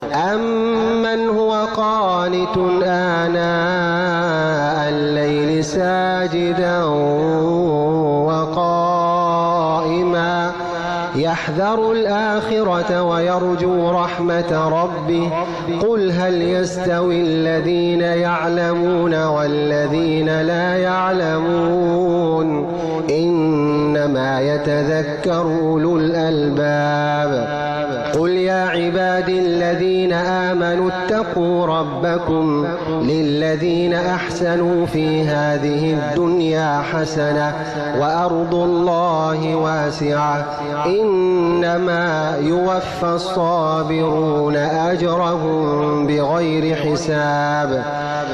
سورة الزمربصوت الشيخ خالد الغامدي .... ثم رفعها للشيخ الدكتور خالد الغامدي